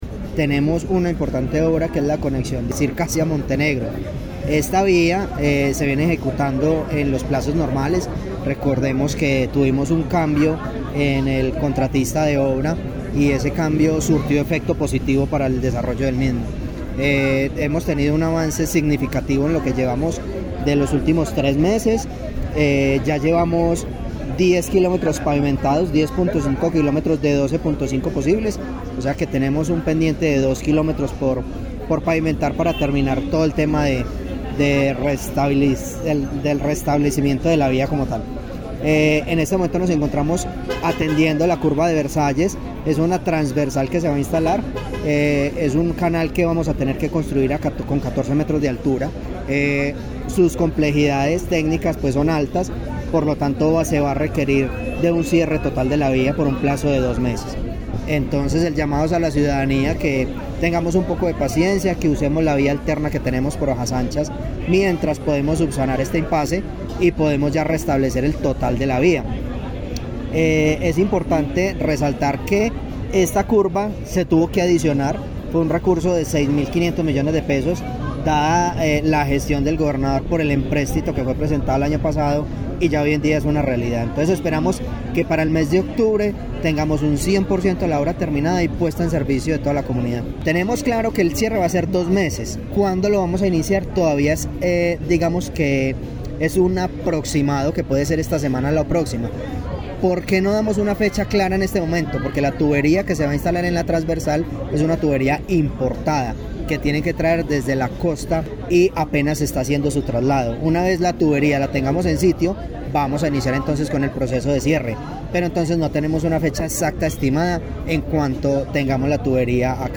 Audio de Héctor David Guzmán, secretario de Aguas e Infraestructura, sobre cierre programado entre Circasia y Montenegro:
Hector-David-Guzman-secretario-de-Aguas-e-Infraestructura-sobre-cierre-programado-entre-Circasia-y-Montenegro-2.mp3